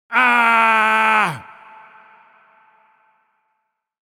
High-energy male scream sound effect with a loud, sustained vocal burst and clear, dry acoustic tone. Perfect for comedic memes, funny scream clips, jump scares, cartoon sound effects, or expressive character reactions, it hits abruptly and tapers naturally.
Aaaah-sound-effect.mp3